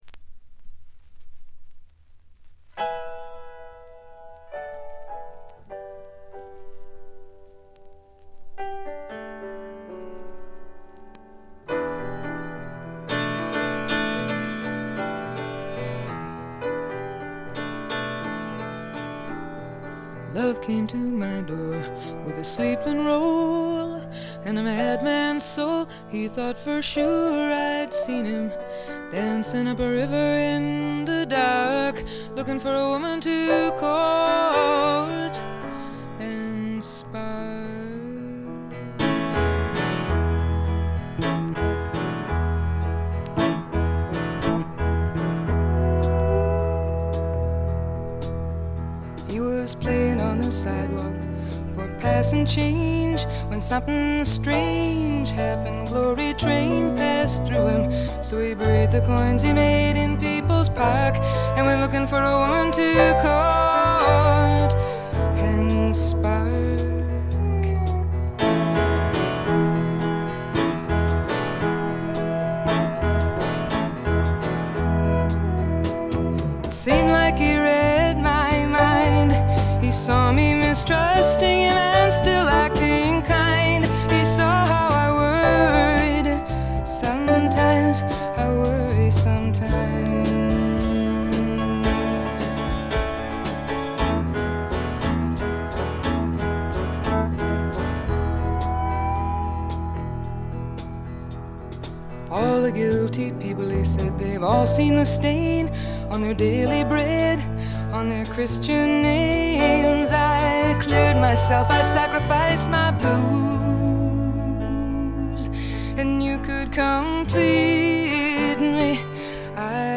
*** LP ： USA 74
ほとんどノイズ感無し。
試聴曲は現品からの取り込み音源です。
Chimes